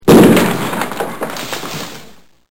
Tear gas boom